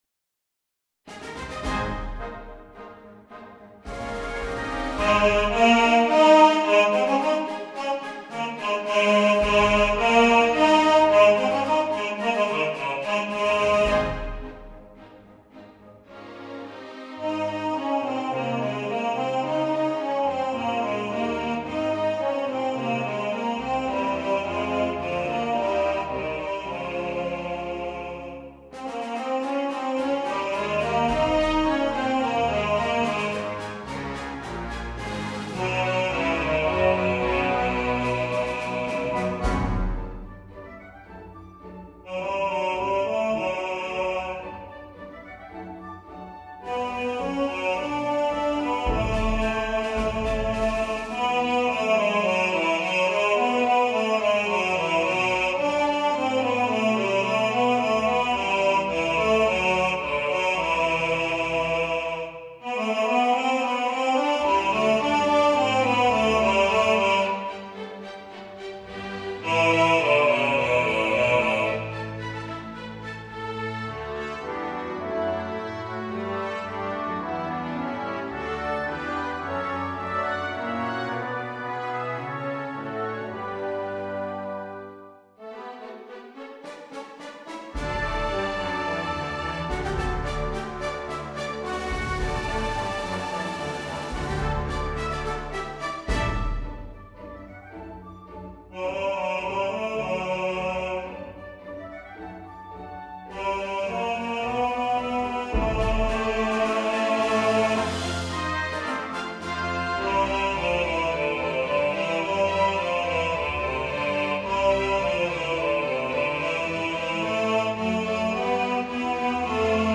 Spirit Of The Season Bass | Ipswich Hospital Community Choir